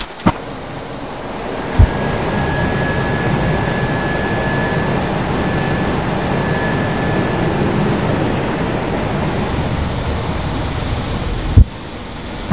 A320_gear.wav